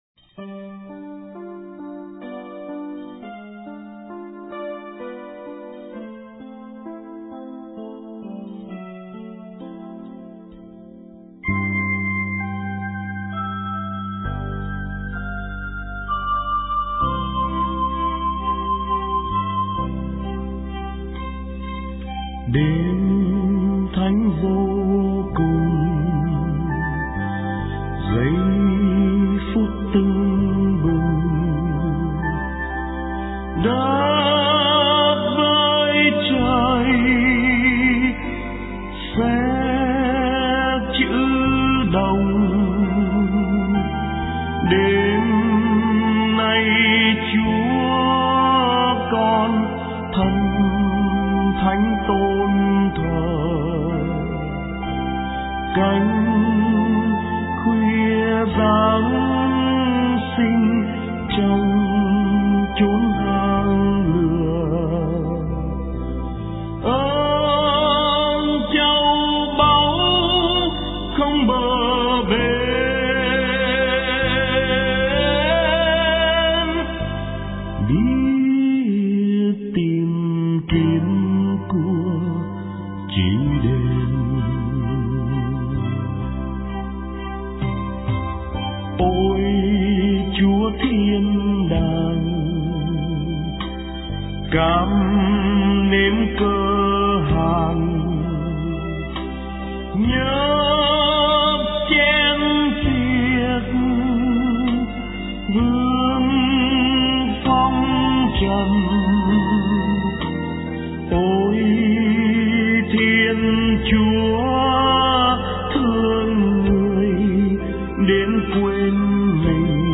* Thể loại: Giáng Sinh